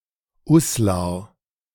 Uslar (German pronunciation: [ˈuslaːɐ̯]
De-Uslar.ogg.mp3